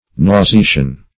Search Result for " nauseation" : The Collaborative International Dictionary of English v.0.48: Nauseation \Nau`se*a"tion\, n. The act of nauseating, or the state of being nauseated.